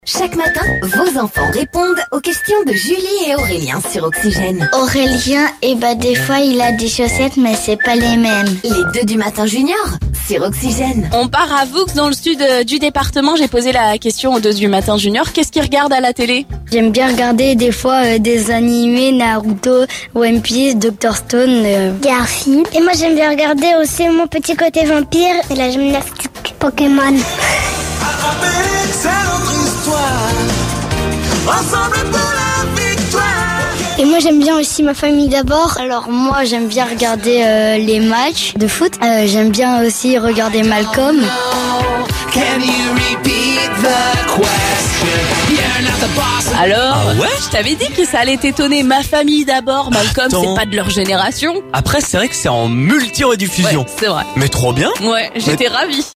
Écouter le podcast Télécharger le podcast Écoutons les enfants Seine-et-Marnais nous expliquer ce qu'il regarde à la télé...et il y a des programmes que l'on connait !